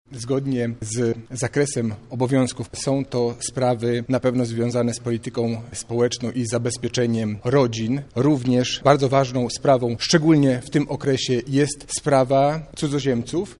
Będę służył jak tylko potrafię najlepiej dla dobra naszego kraju – mówi Robert Gmitruczuk, nowy wicewojewoda